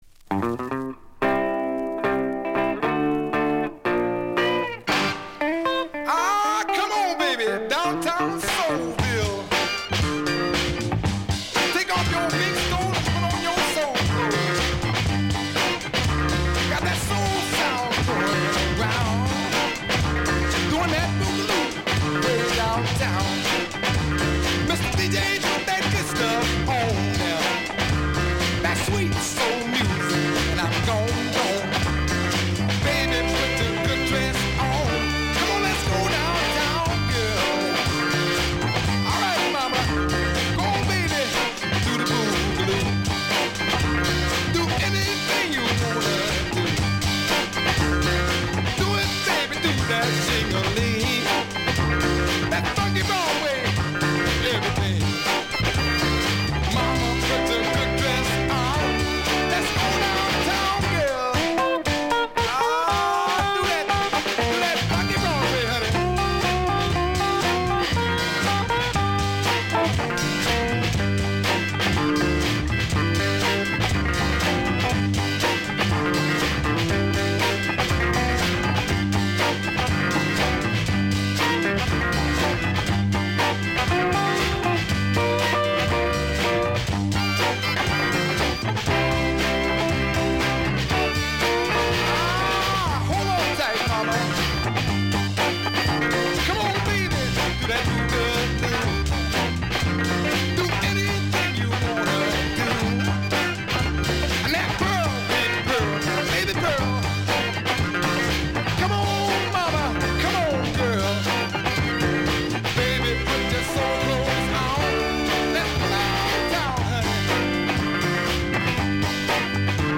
1 Live from WFMU Record Fair from Nov 1, 2024